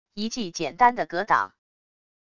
一记简单的格挡wav下载